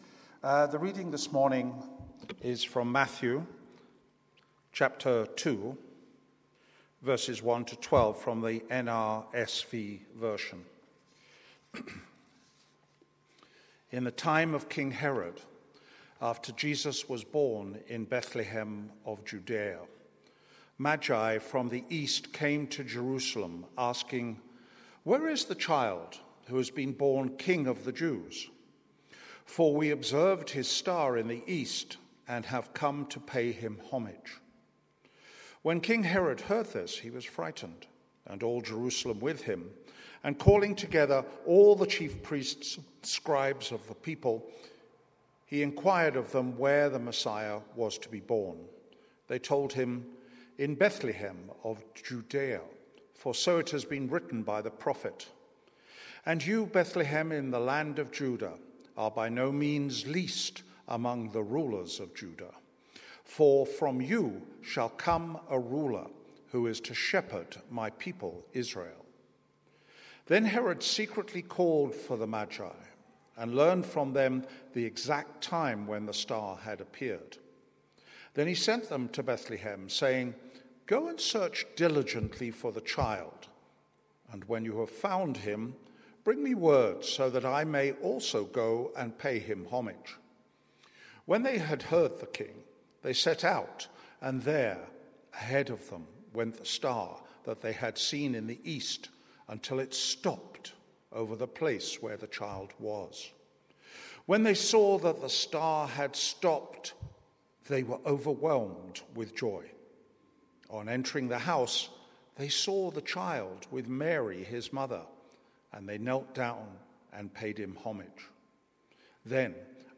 Lectionary Preaching – Second Sunday After Christmas
Sermons
Fusion Service from Trinity Methodist Church, Linden, Johannesburg